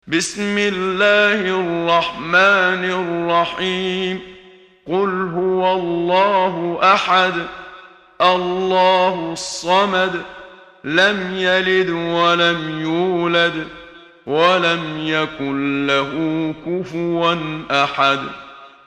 محمد صديق المنشاوي – ترتيل – الصفحة 9 – دعاة خير